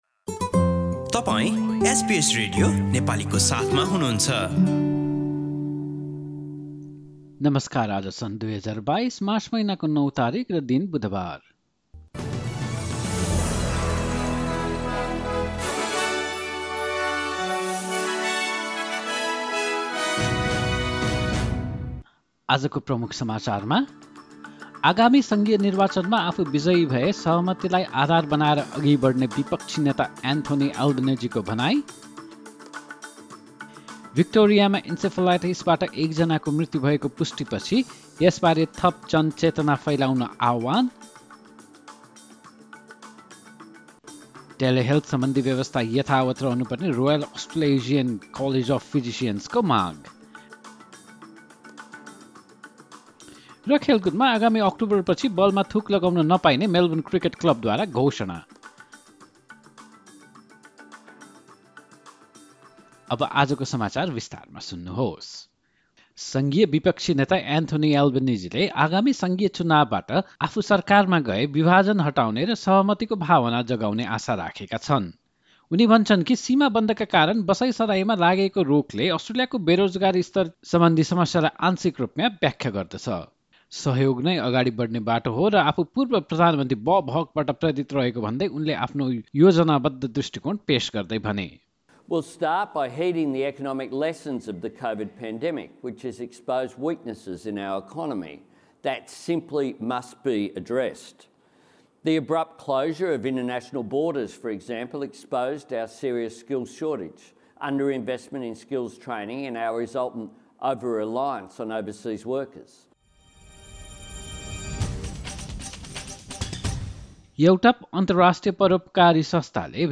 एसबीएस नेपाली अस्ट्रेलिया समाचार: बुधवार ९ मार्च २०२२